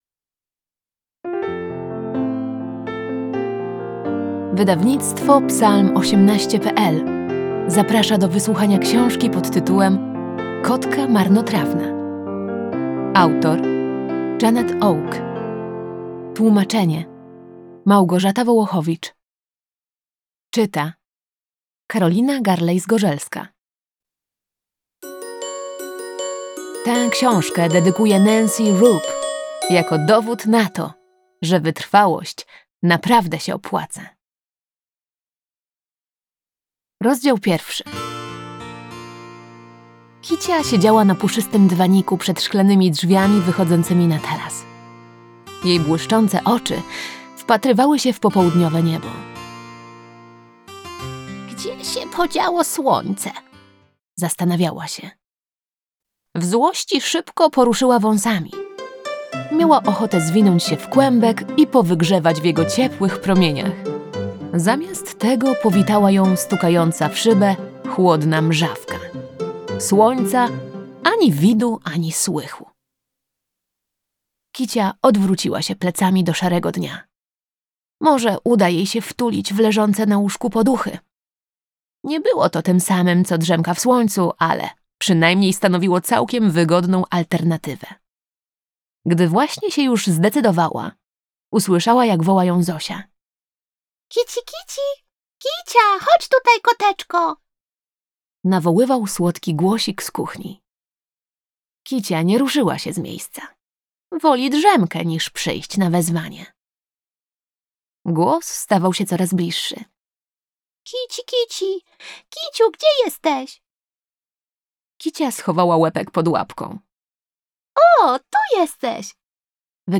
Kotka marnotrawna - Audiobook